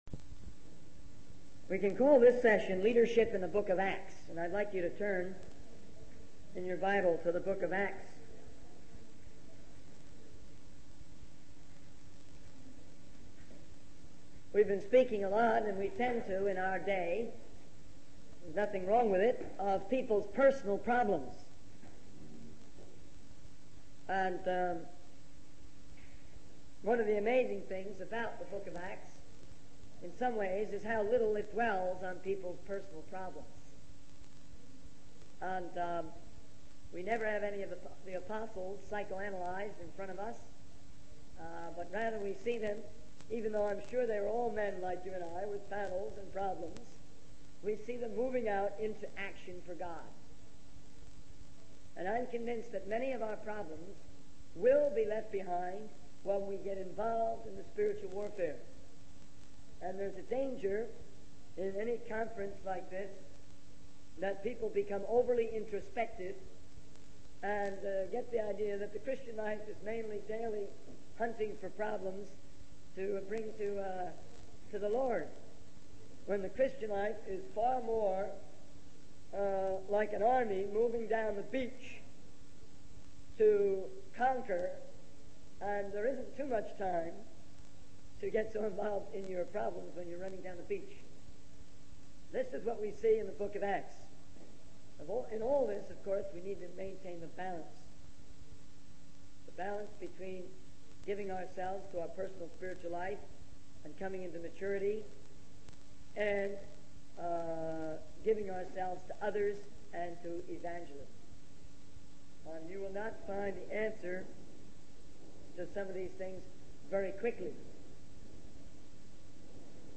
In this sermon, the speaker emphasizes the need for Christians to be more realistic and aware of the world's challenges.